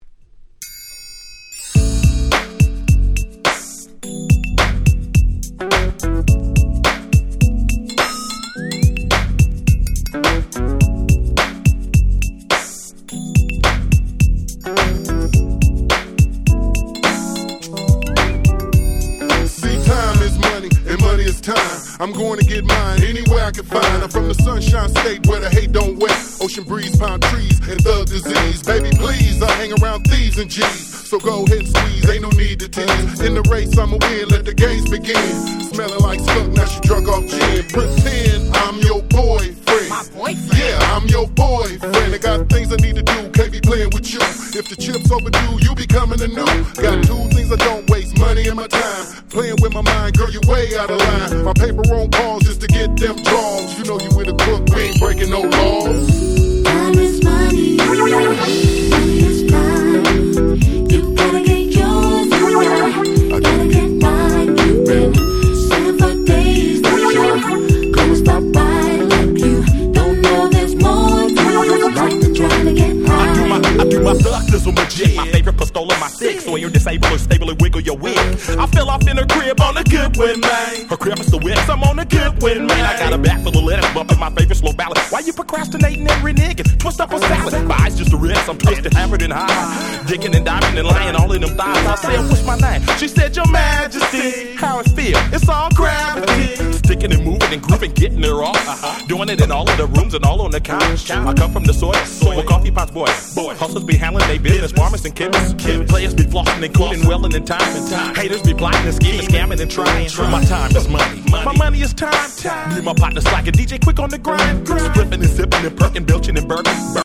01' Very Nice West Coast Hip Hop !!
最高のマイナーG-Rap !!